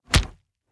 punch10.ogg